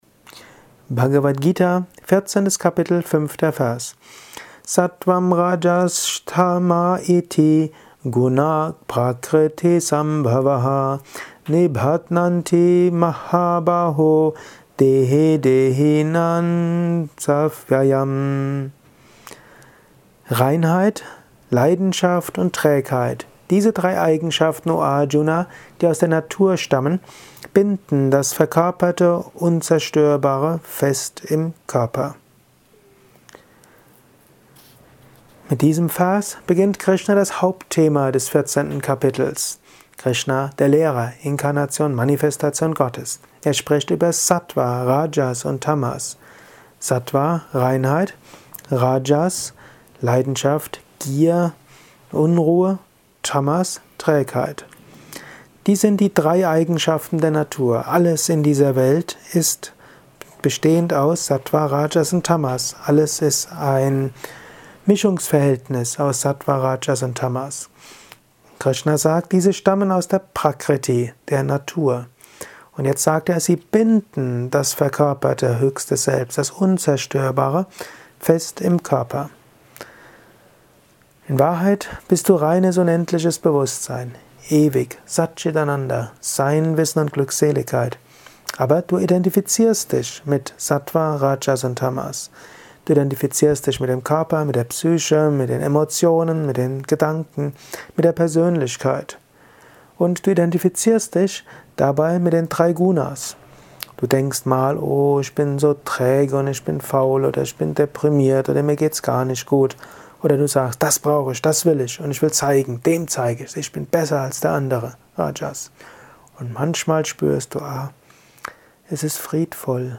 Dies ist ein kurzer Kommentar als Inspiration für den heutigen